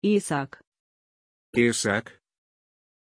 Pronuncia di Iisak
pronunciation-iisak-ru.mp3